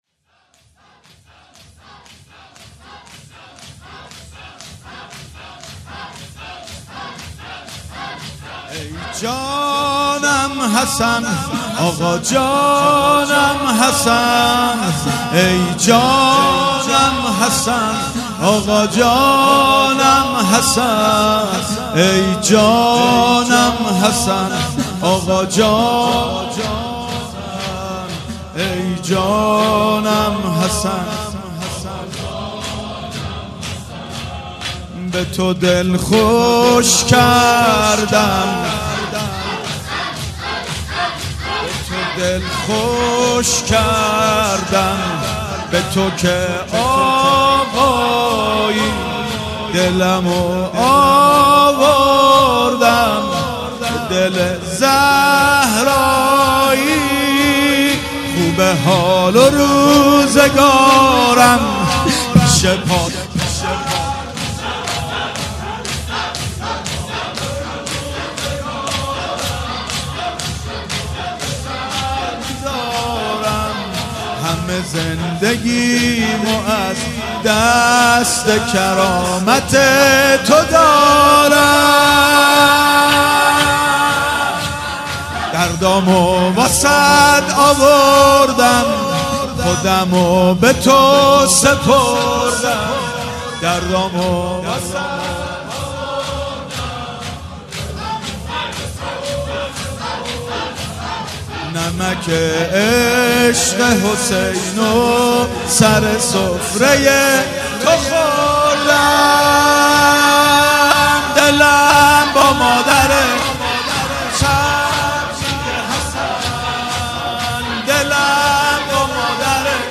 نوحه شور به تو دل خوش کردم